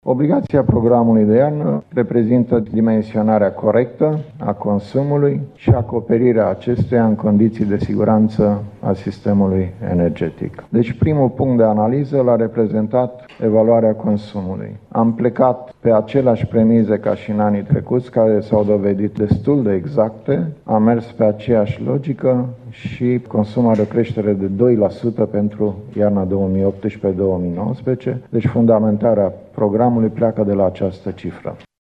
Iarna aceasta, în România, consumul de energie ar putea fi mai mare cu 2 la sută, faţă de anul trecut, estimează specialiştii din Ministerul Energiei. Această estimare a stat la baza întocmirii Programului de iarnă din acest an, a declarat astăzi secretarul de stat din Ministerul Energiei, Doru Vişan: